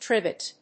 音節triv・et 発音記号・読み方
/trívɪt(米国英語)/